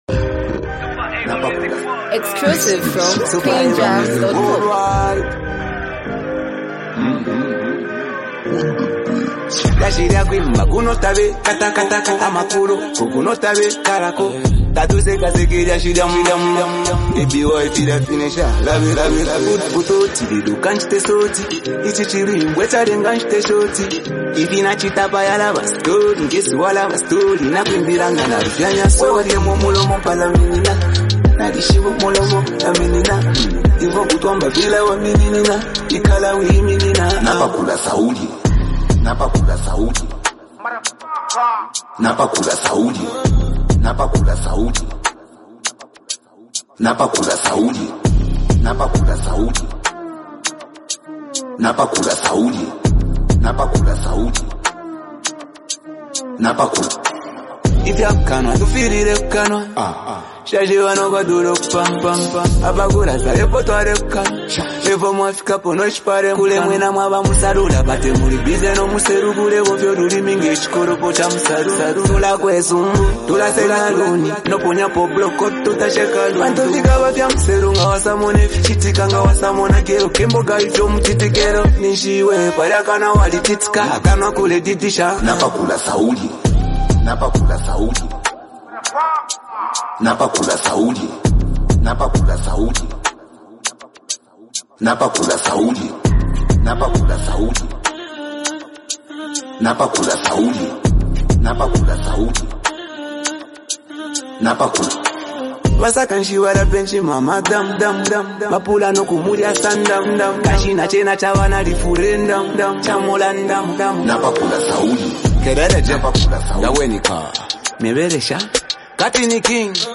emotionally charged song
expressive vocals